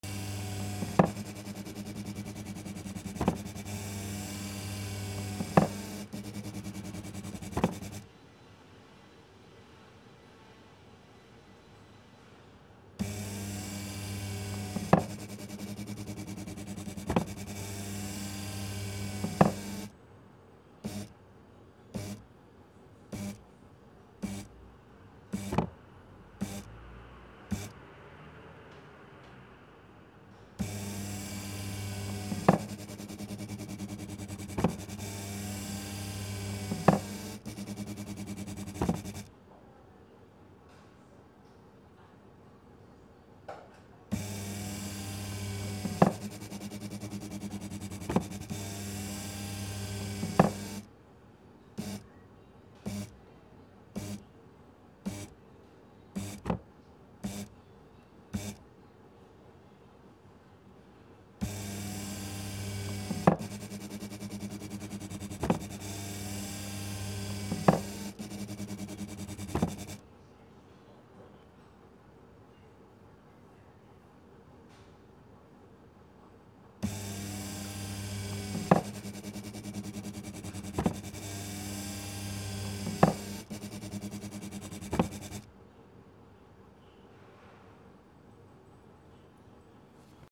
マッサージ機2 モーター音◇